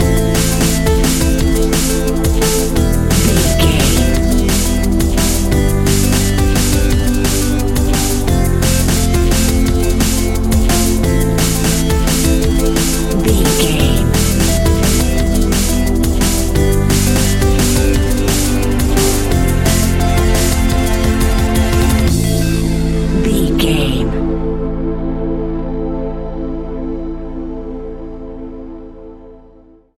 royalty free music
Aeolian/Minor
Fast
futuristic
hypnotic
industrial
dreamy
frantic
aggressive
powerful
synthesiser
drums
electronic
sub bass
synth leads
synth bass